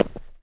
mining sounds